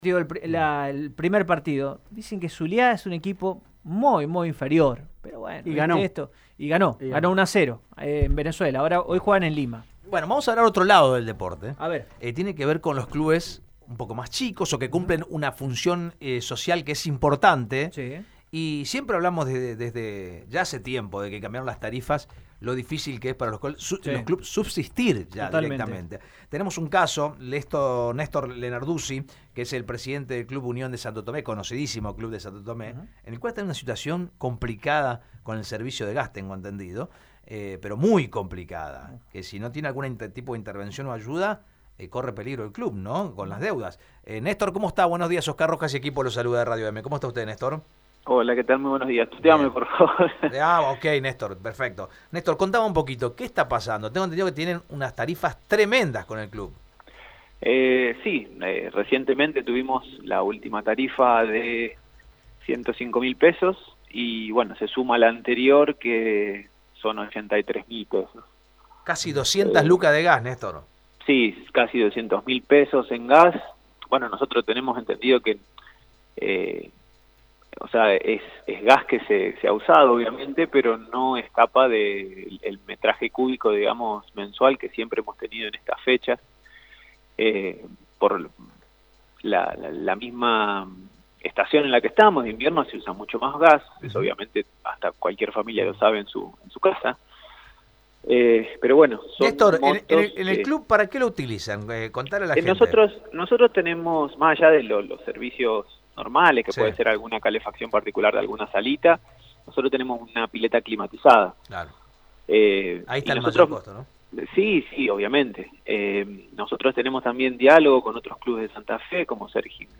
La institución deportiva santotomesina se ve afectada por la elevadas sumas que les llegan en las facturas del gas. En dialogo con Radio EME